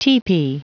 Prononciation du mot tepee en anglais (fichier audio)
Prononciation du mot : tepee